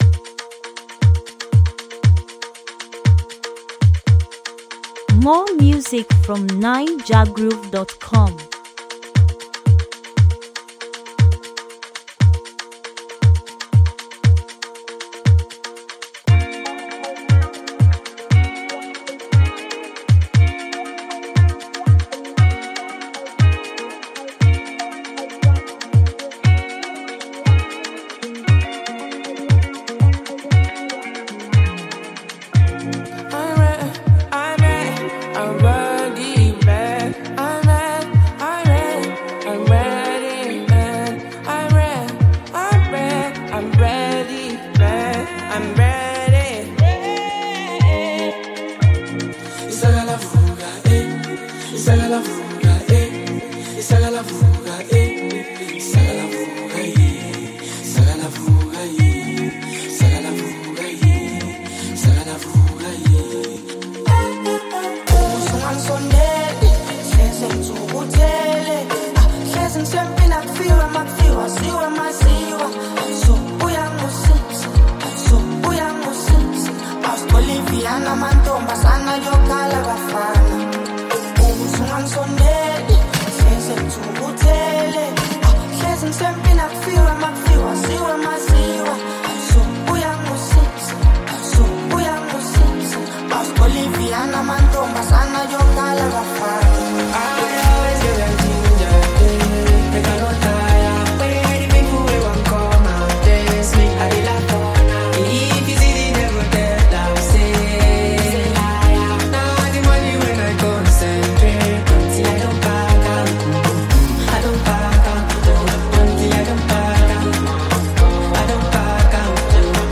Latest, SA-Music, Naija-music